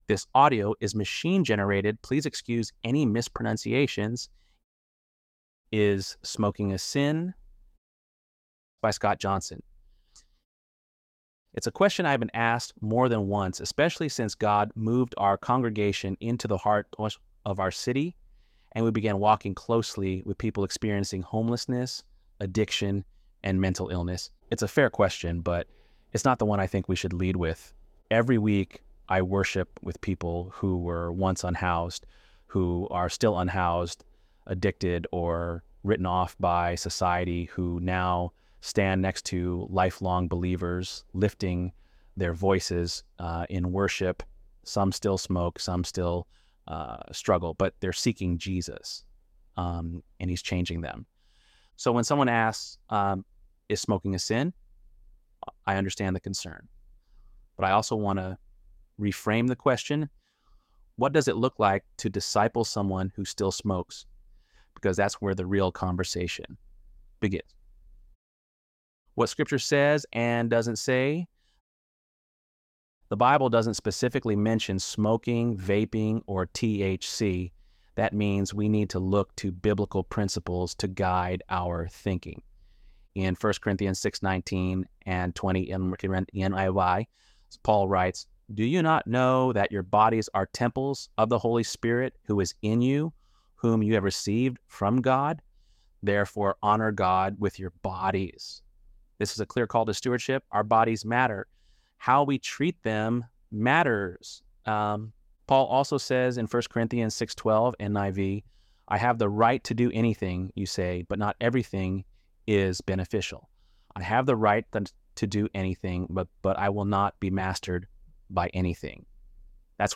ElevenLabs_12_6.mp3